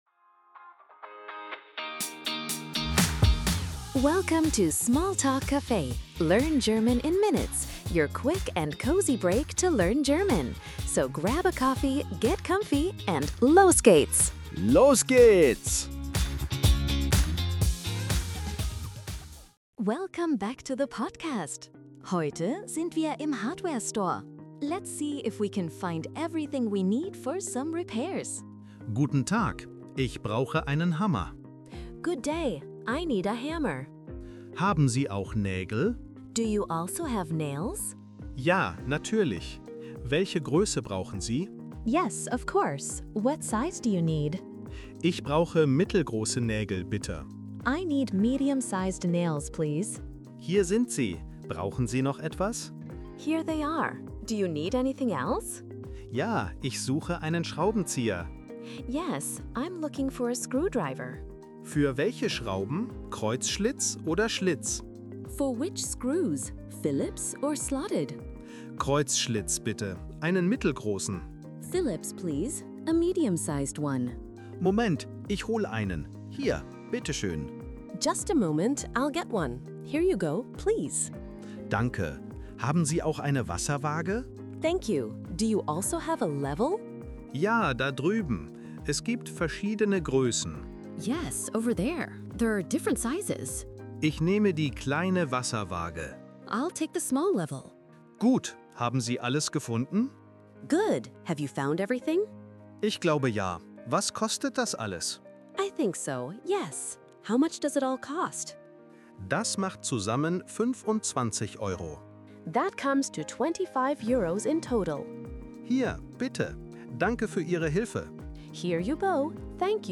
Tune in for quick, real-life dialogues, helpful tips, and the confidence boost you need to navigate …